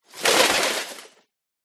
0:00 Group: Tiere ( 544 190 ) Rate this post Download Here!